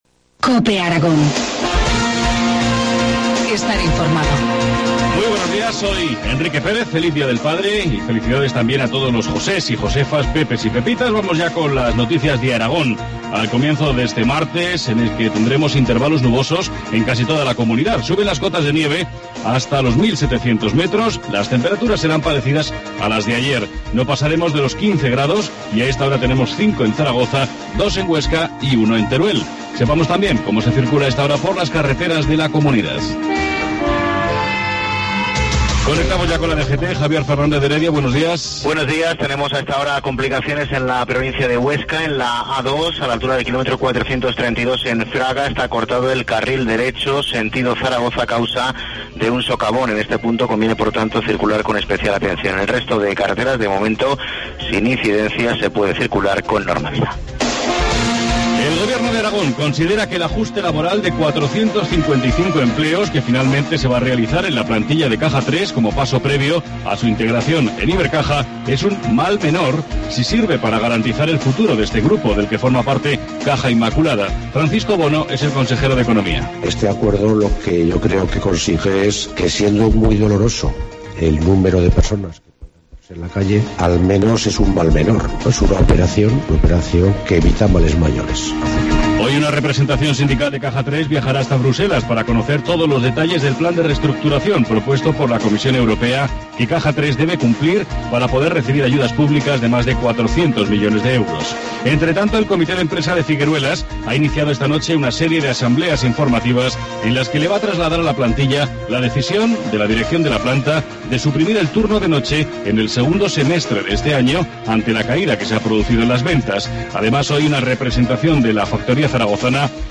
Informativo matinal, martes 19 de marzo, 7.25 horas